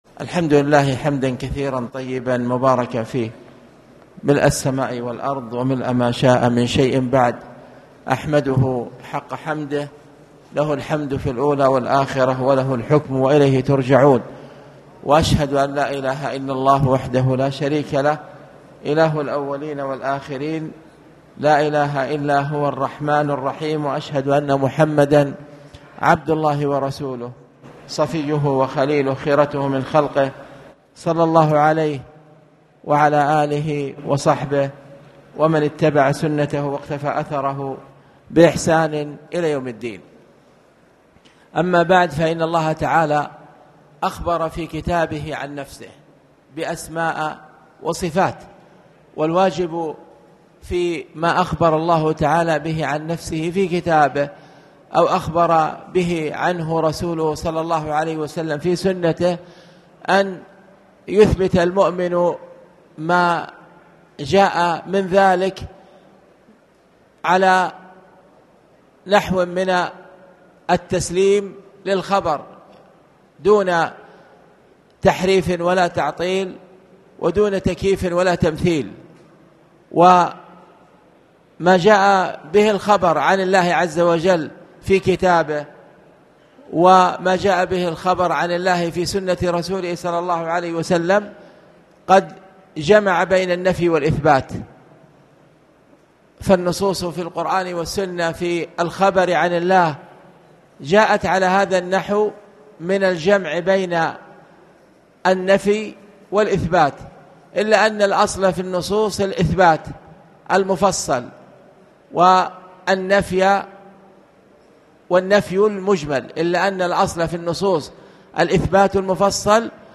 تاريخ النشر ٧ ربيع الأول ١٤٣٩ هـ المكان: المسجد الحرام الشيخ